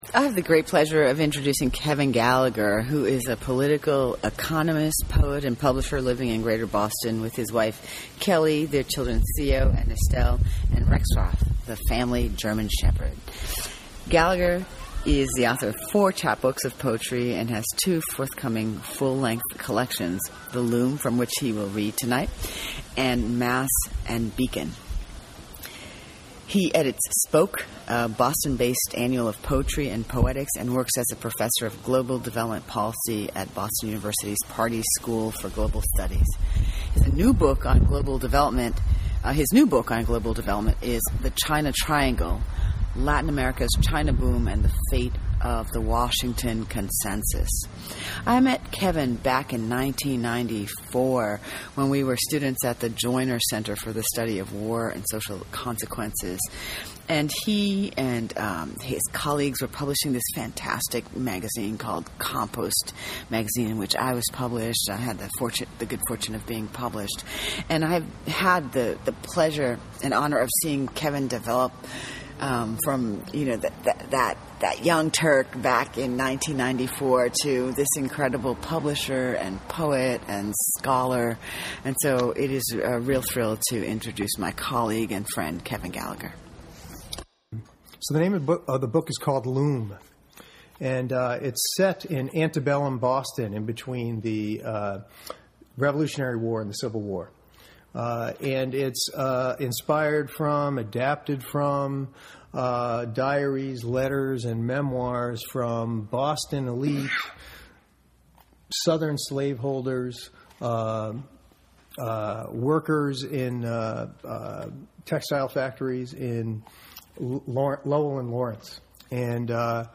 give a reading